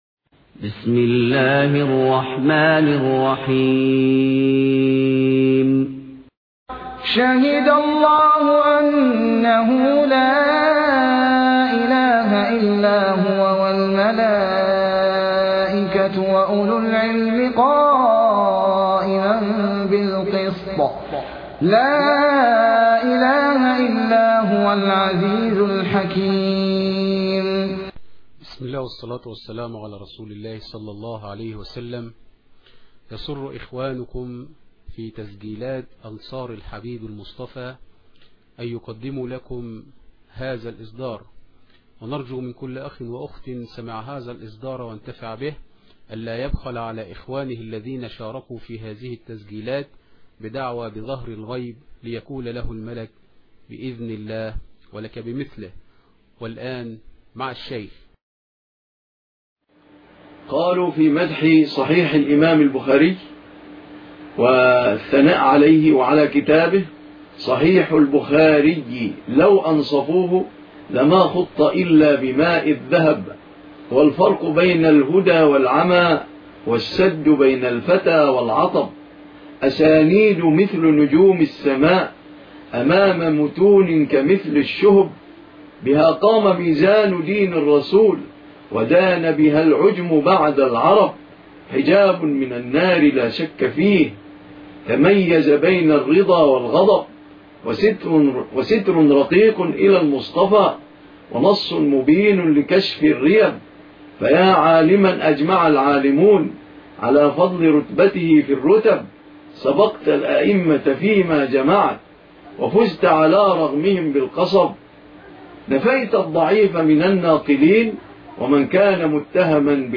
الدرس الخامس ( شرح العقيدة الواسطية